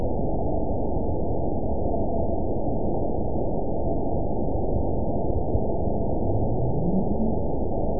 event 920385 date 03/20/24 time 23:09:55 GMT (1 month, 1 week ago) score 9.27 location TSS-AB03 detected by nrw target species NRW annotations +NRW Spectrogram: Frequency (kHz) vs. Time (s) audio not available .wav